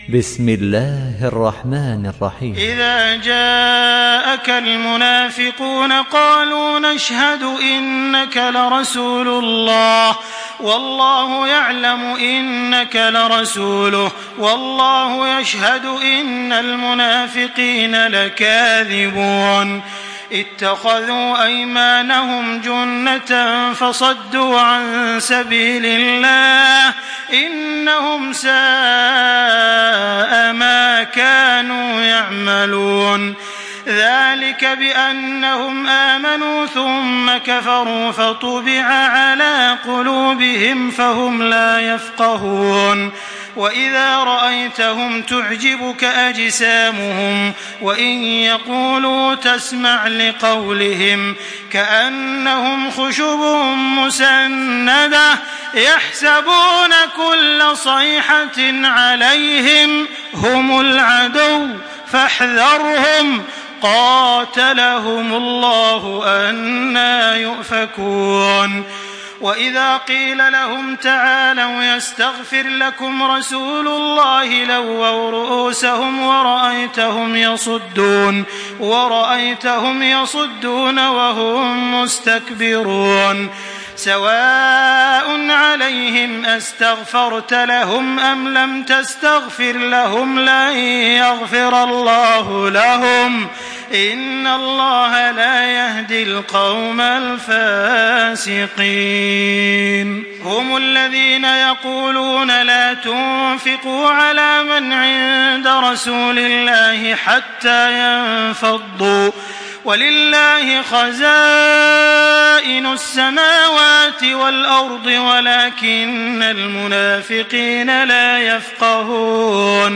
Download Surah Al-Munafiqun by Makkah Taraweeh 1425
Murattal